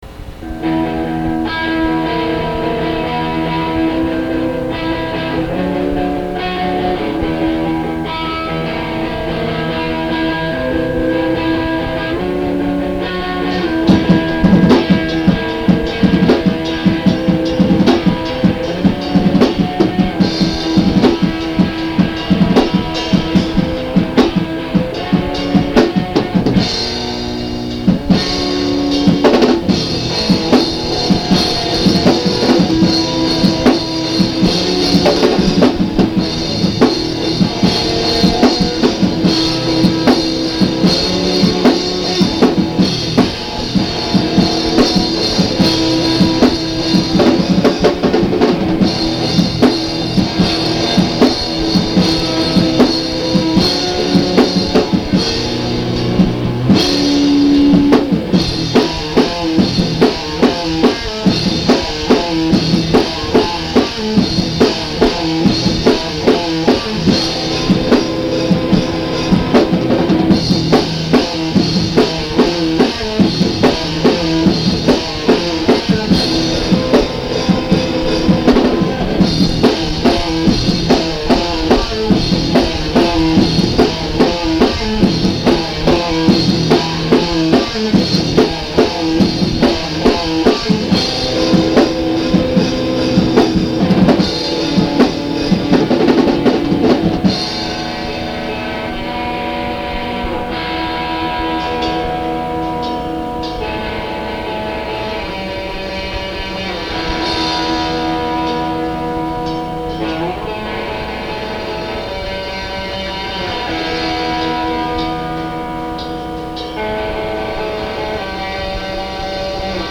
Anyways, I put some early mixes of the songs up so you can get an idea of what we sound like.
We are from Kamloops BC, and we are the best crust band in town.